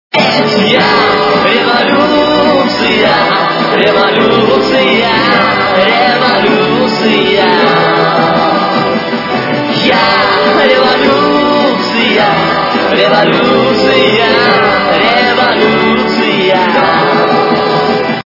украинская эстрада